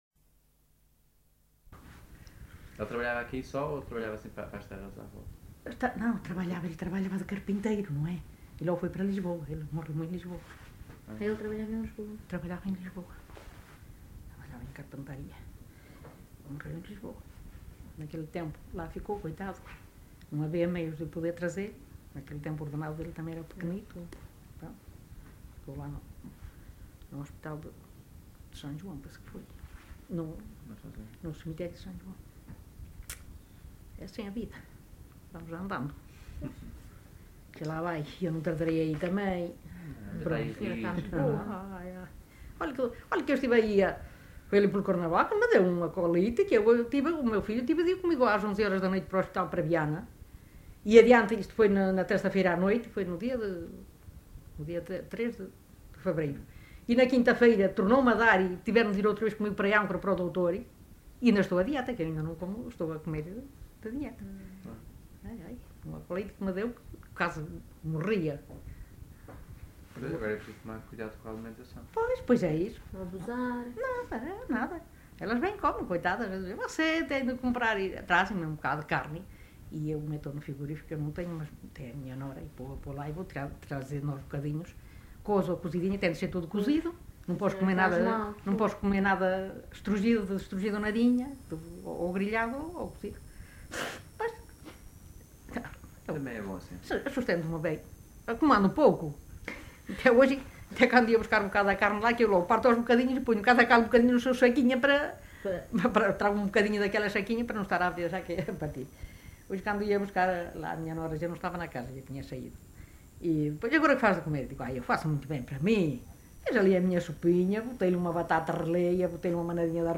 LocalidadeSão Lourenço da Montaria (Viana do Castelo, Viana do Castelo)